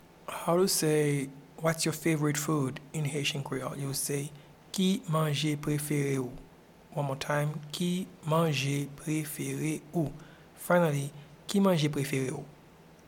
Pronunciation and Transcript:
Whats-your-favorite-food-in-Haitian-Creole-Ki-manje-prefere-ou.mp3